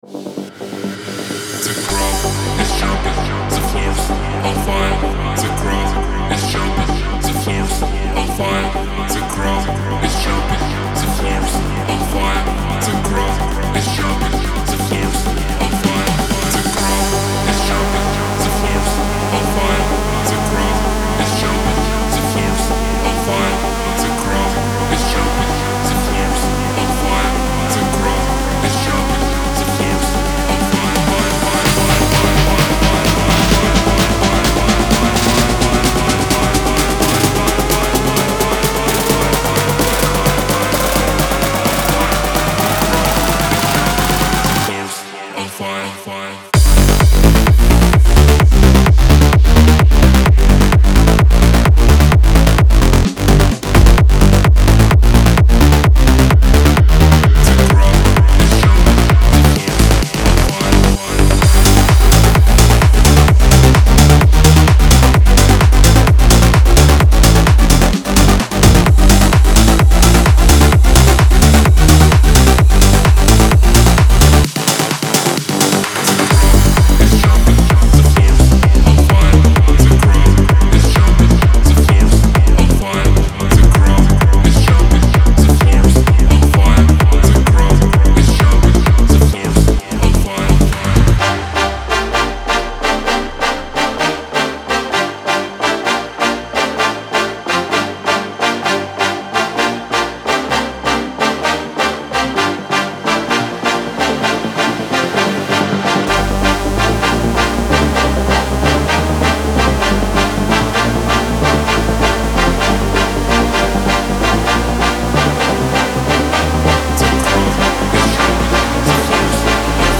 • Жанр: Electronic, House